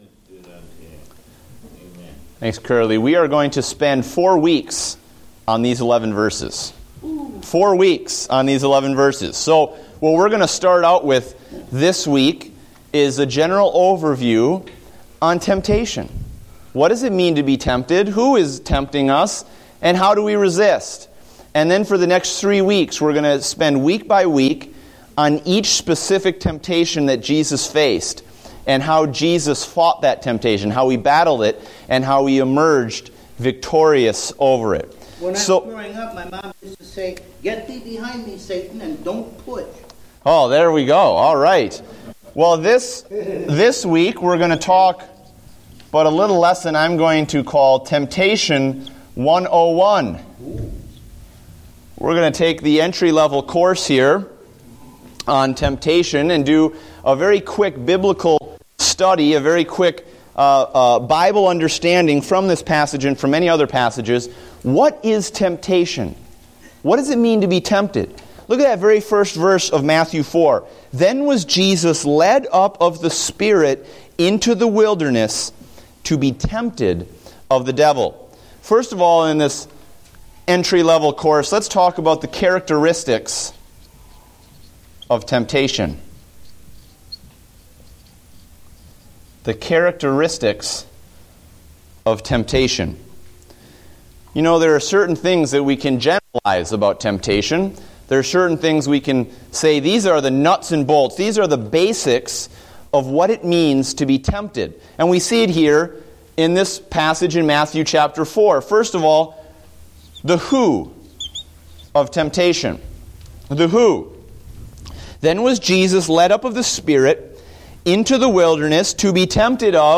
Date: February 8, 2015 (Adult Sunday School)